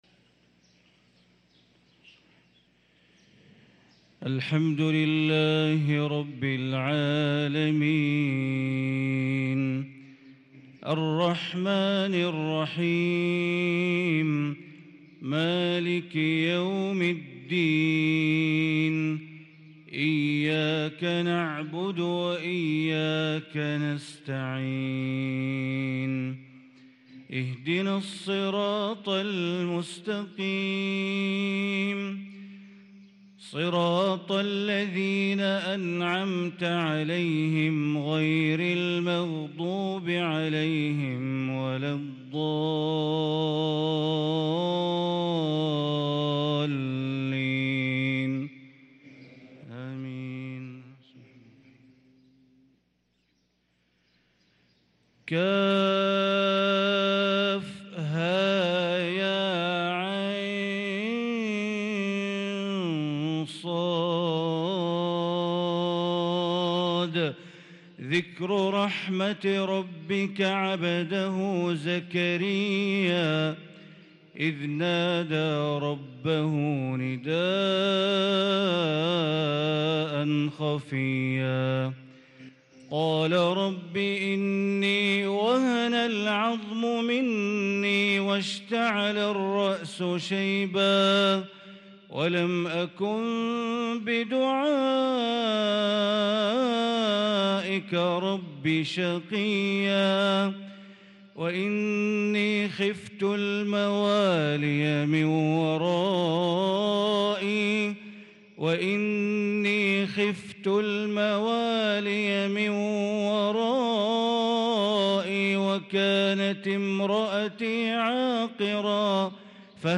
صلاة الفجر للقارئ بندر بليلة 5 ربيع الآخر 1444 هـ
تِلَاوَات الْحَرَمَيْن .